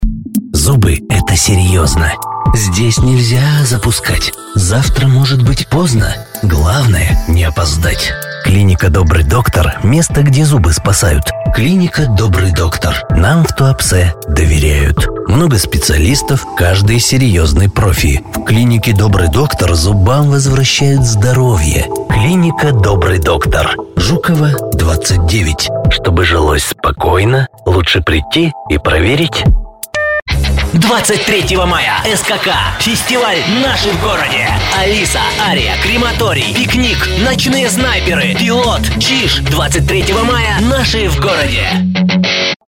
Rusça Seslendirme
Rusça seslendirme hizmetini Dünyanın en meşhur seslendirme ve dublaj sanatçıları ile profesyonel stüdyomuzda, deneyimli ekibimizle sağlıyoruz.